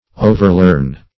Search Result for " overlearn" : The Collaborative International Dictionary of English v.0.48: overlearn \o`ver*learn"\, v. t. To learn (a fact or skill) repetitively, beyond the point where it can be immediately recalled; in experimental psychology, to continue to learn beyond the point where the criterion of adequate learning has been reached.